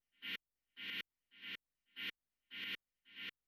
Index of /90_sSampleCDs/Best Service ProSamples vol.54 - Techno 138 BPM [AKAI] 1CD/Partition C/UK PROGRESSI
NOISE AMBI-R.wav